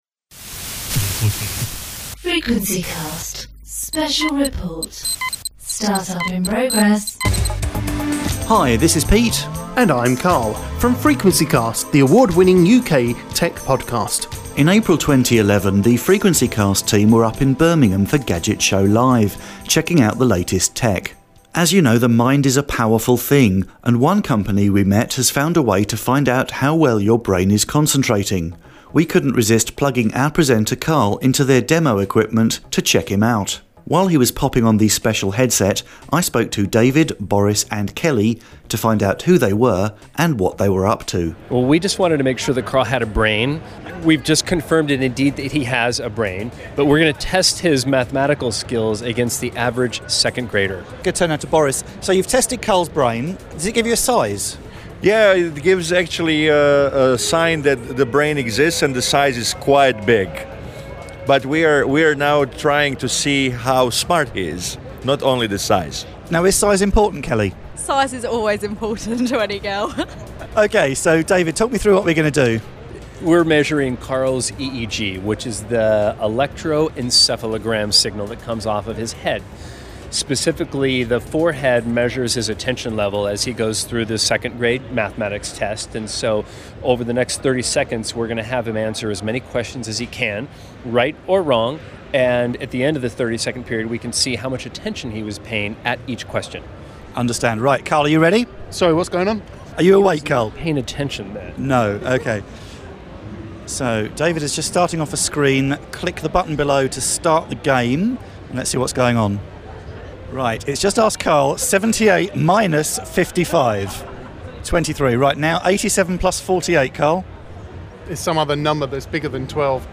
The stand was gathering quite a crowd keen to try a demo of a PC game that's driven by decisions from a headset getting feedback from the player's brain.